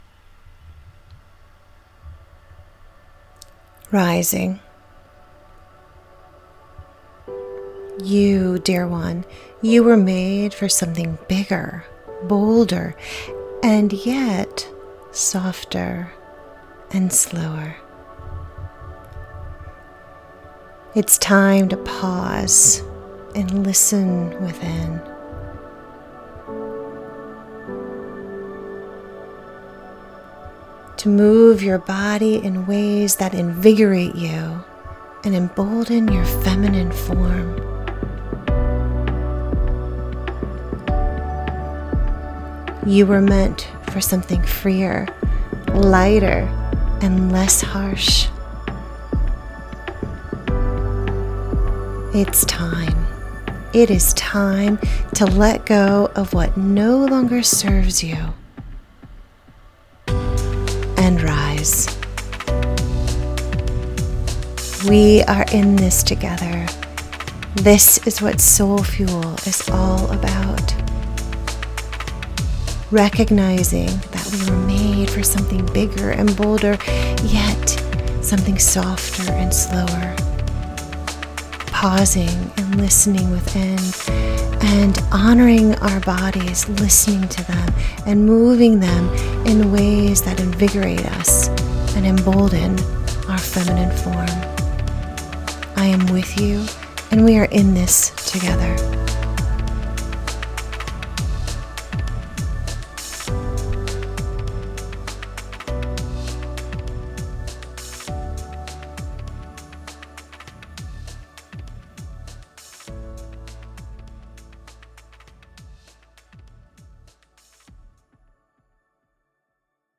Your voice is so soothing.
Rising-poem-for-Soul-Fuel.mp3